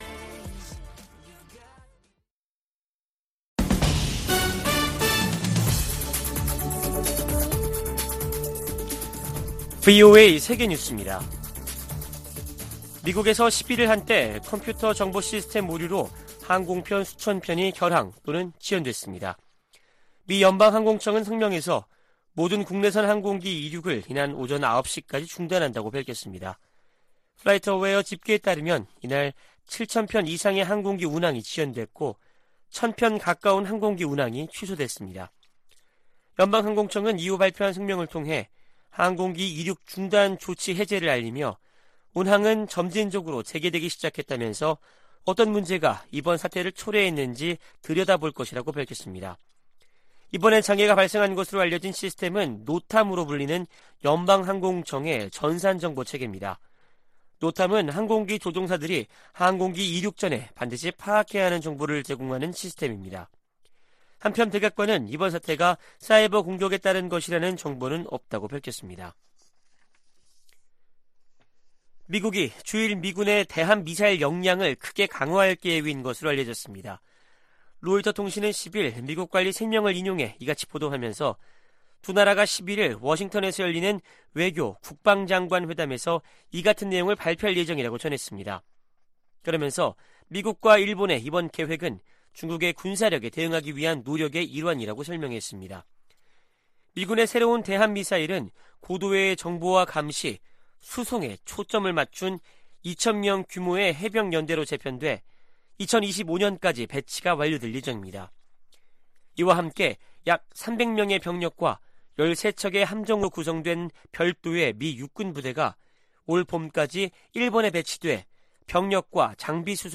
VOA 한국어 아침 뉴스 프로그램 '워싱턴 뉴스 광장' 2023년 1월 12일 방송입니다. 윤석열 한국 대통령은 북한의 잇단 도발 행위들은 한국의 대응 능력을 강화하고, 미한일 간 안보 협력을 강화하는 결과를 가져올 것이라고 말했습니다. 미국과 한국은 다음달 북한의 핵 공격 시나리오를 가정한 확장억제수단 운용연습을 실시합니다.